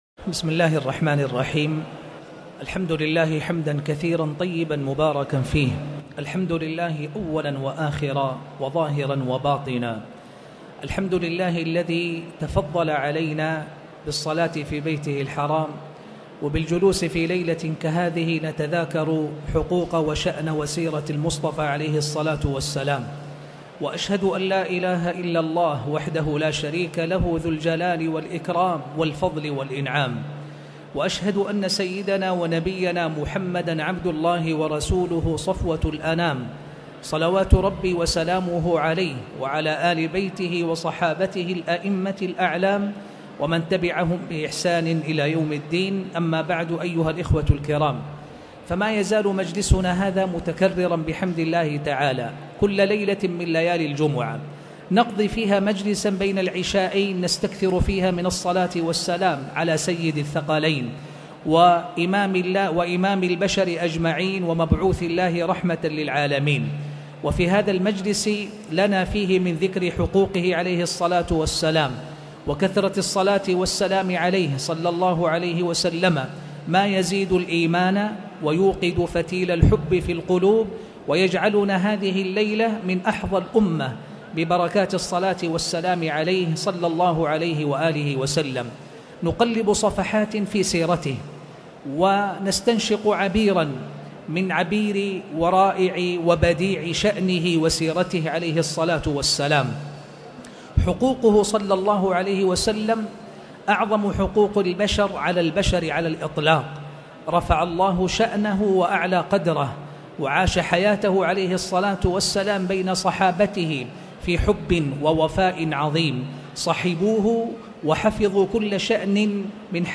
تاريخ النشر ١٢ محرم ١٤٣٨ هـ المكان: المسجد الحرام الشيخ